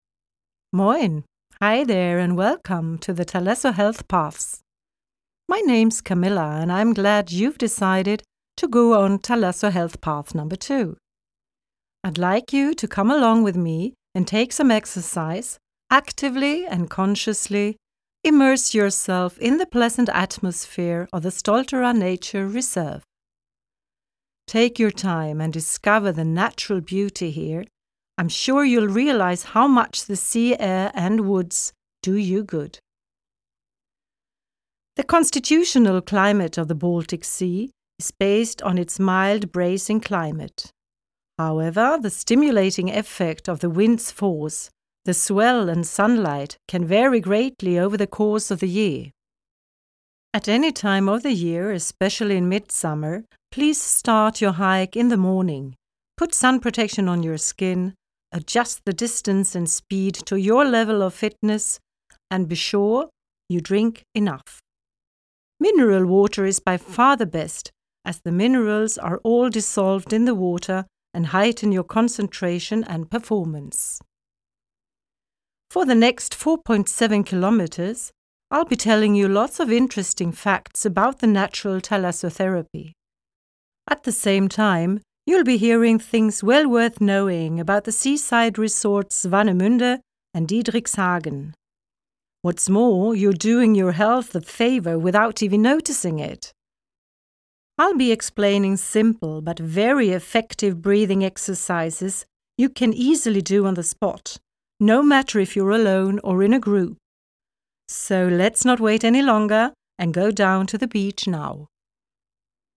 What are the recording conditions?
Responsible for the sound recordings: Sound studio at Stralsund University of Applied Sciences.